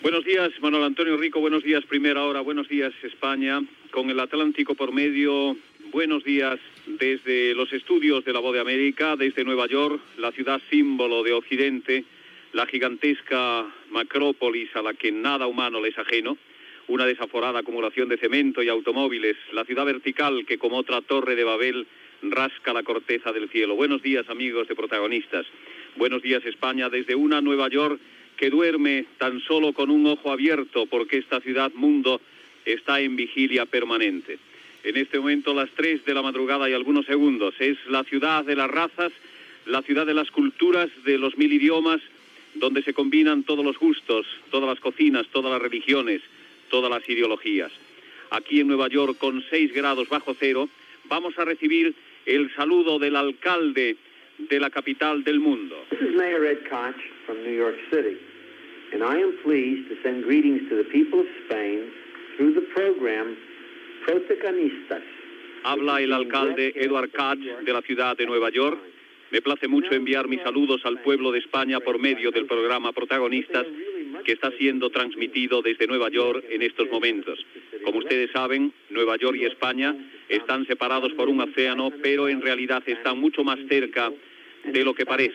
Programa emès des de La Voz de América a Nova York. Salutació de l'alcalde de la ciutat.
Info-entreteniment